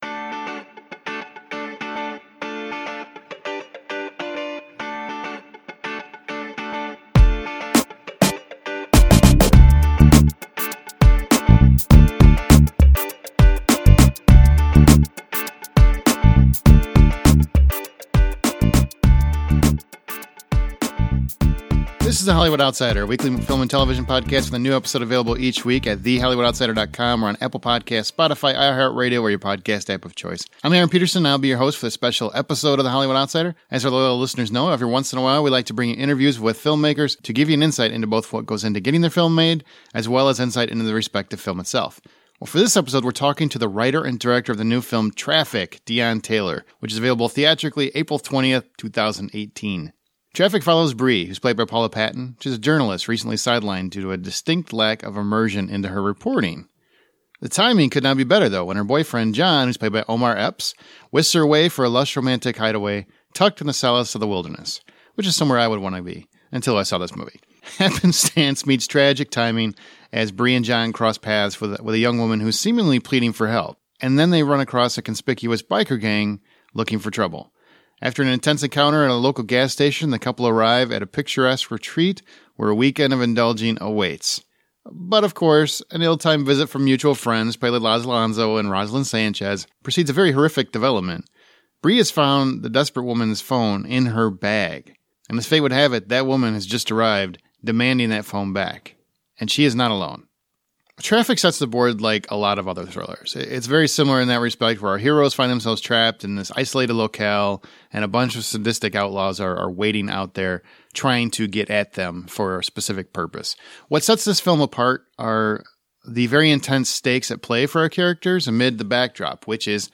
Interview with Writer and Director Deon Taylor | Traffik